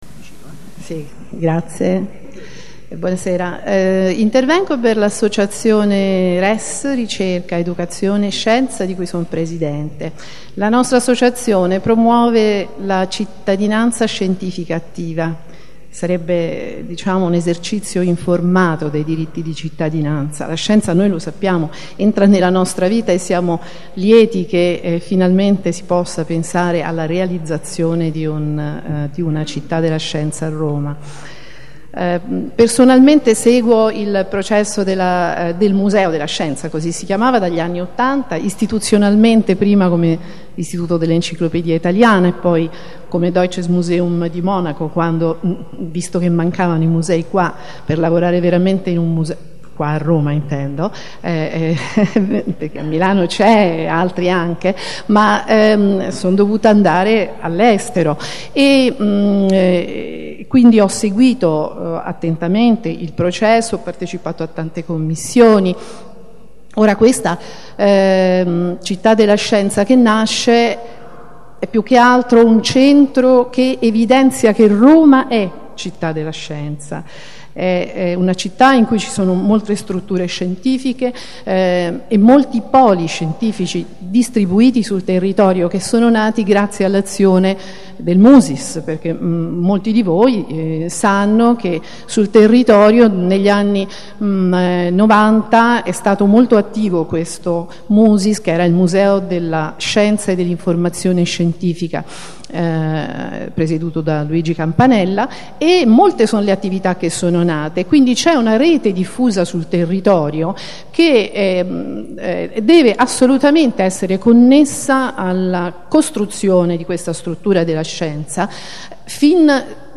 Piano di recupero del Quartiere Città della Scienza - Ascolto audio del secondo incontro partecipativo